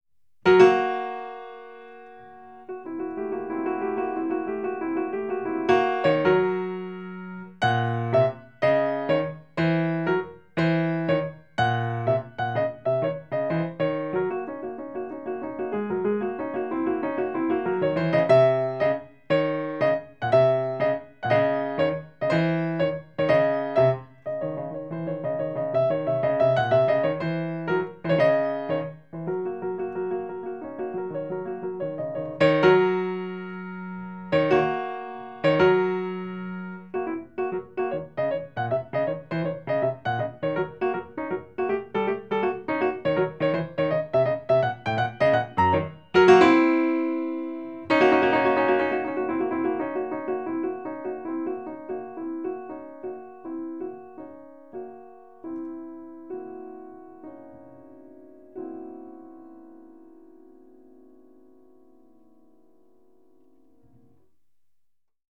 Solos piano